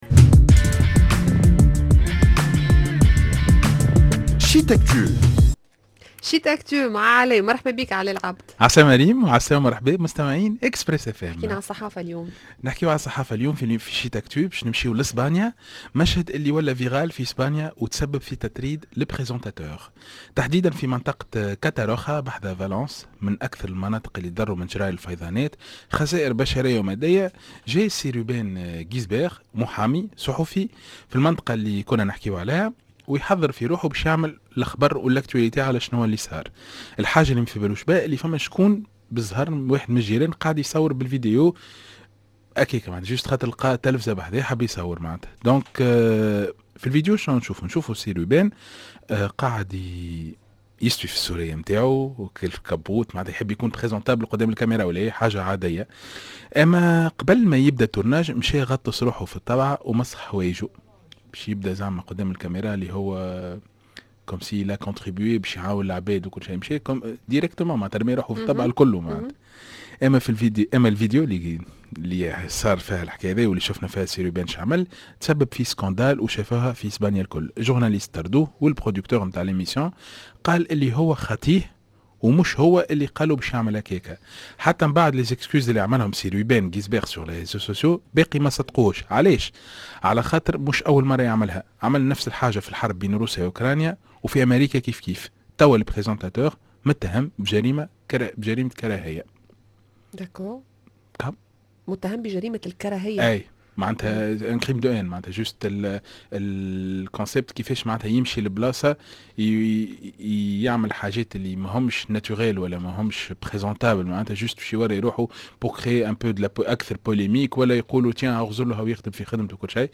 sur les ondes d’Express FM